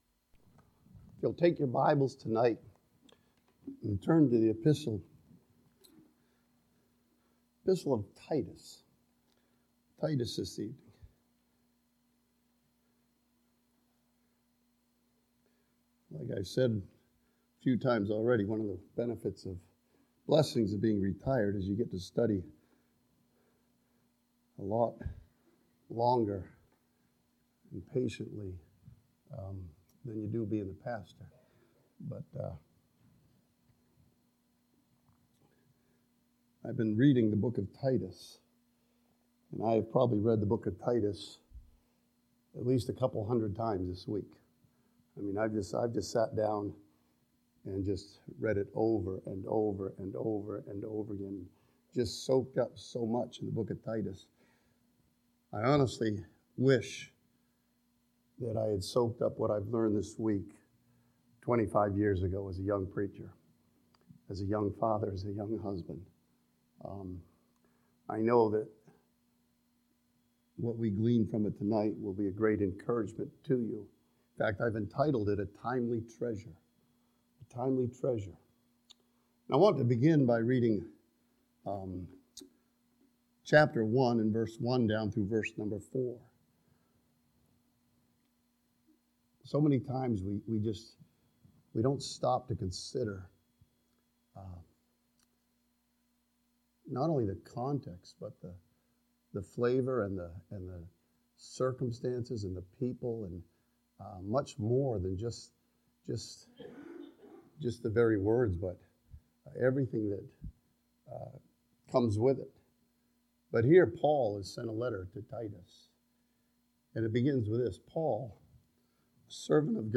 Series: Sunday PM